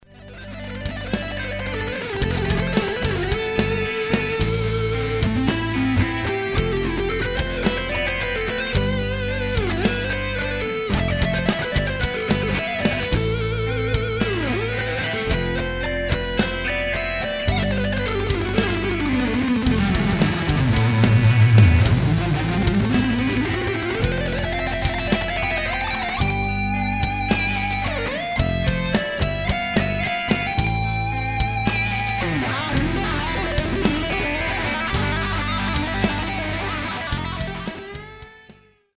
Lead guitars